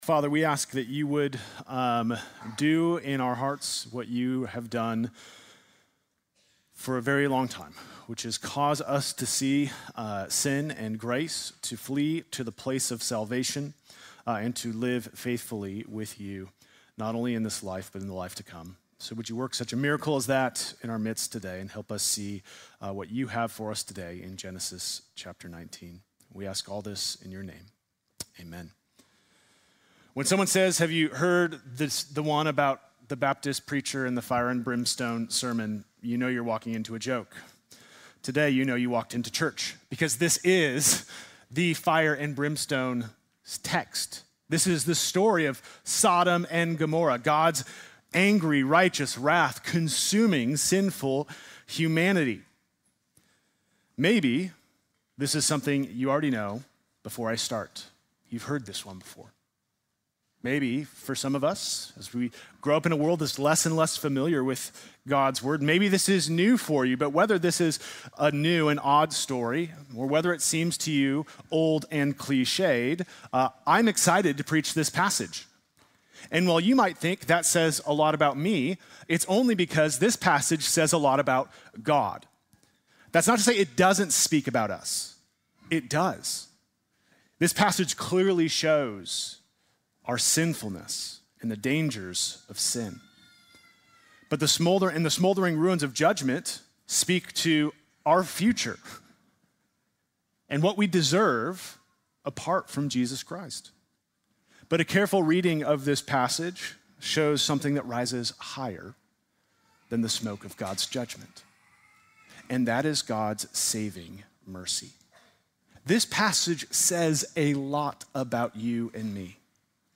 Sunday morning message March 15, 2026: